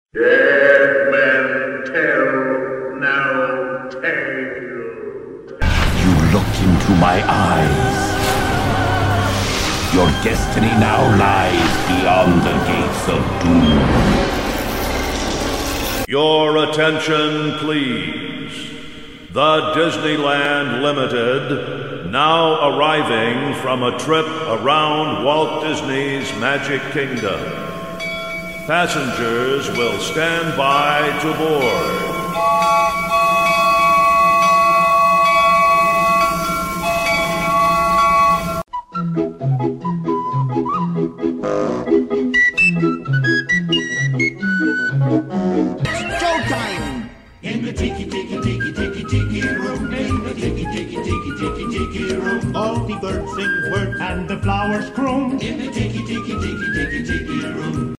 🎧ASMR for Disney nerds.
We’ve had a lot of fun with Disney World sounds, but this time going back to where it all started: Disneyland! Here are five of the best sounds that instantly transport me back to the Happiest Place On Earth.